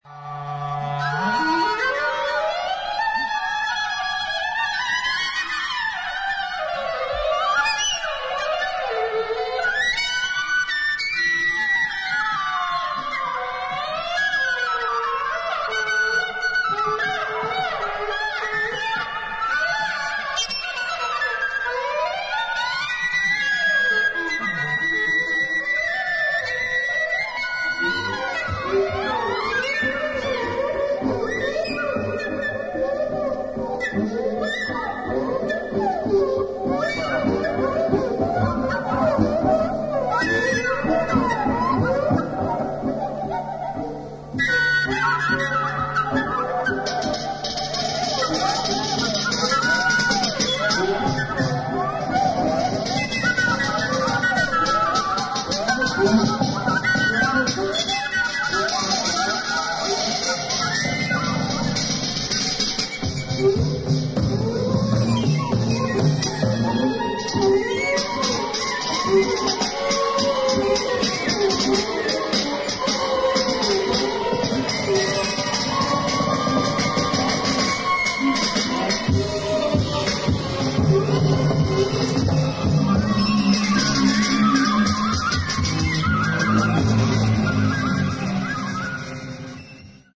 1969年にニューヨーク大学にて録音。